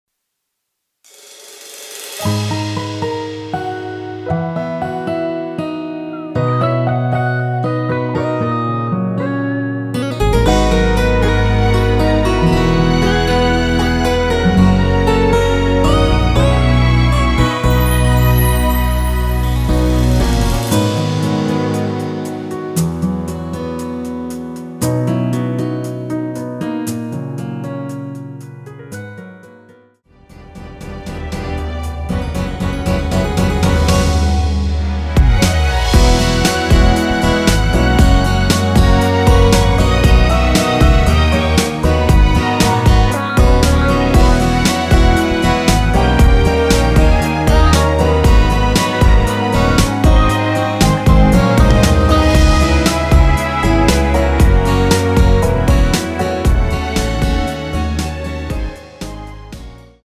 원키에서(-1)내린 MR입니다.(미리듣기 참조)
Abm
◈ 곡명 옆 (-1)은 반음 내림, (+1)은 반음 올림 입니다.
앞부분30초, 뒷부분30초씩 편집해서 올려 드리고 있습니다.